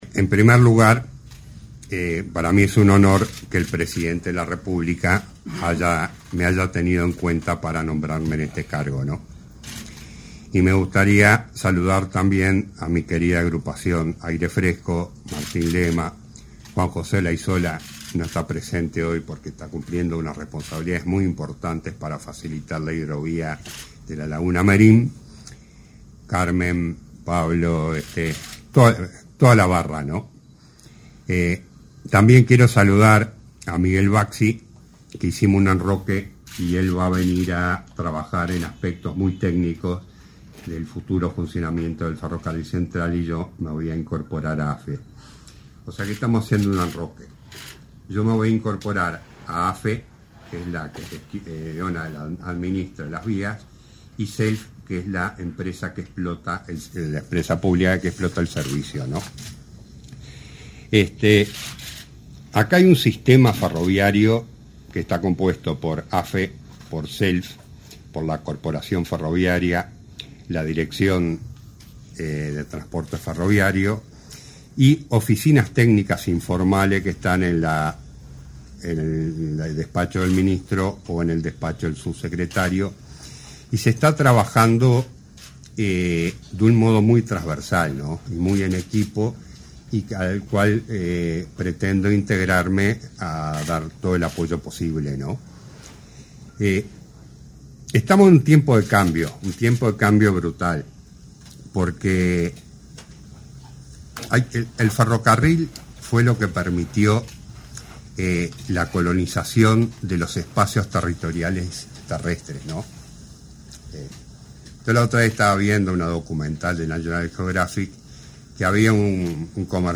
Ceremonia de asunción del presidente del directorio de AFE